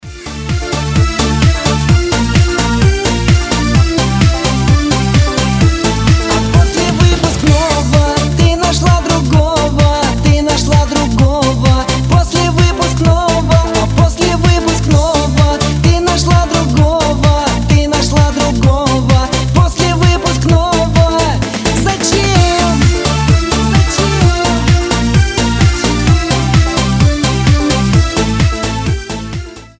ретро , поп , грустные